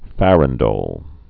(fărən-dōl)